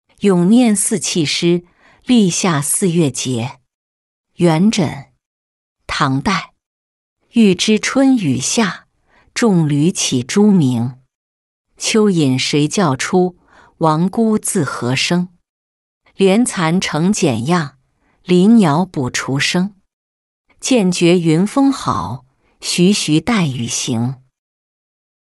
咏廿四气诗·立夏四月节-音频朗读